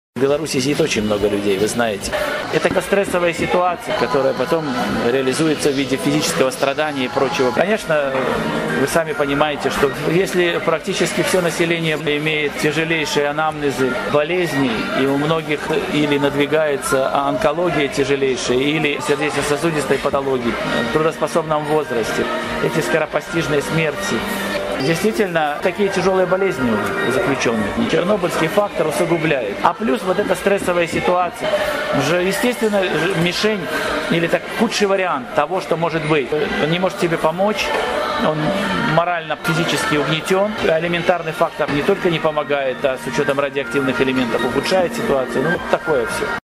Інтэрвію зь Юр'ем Бандажэўскім, турэмныя назіраньні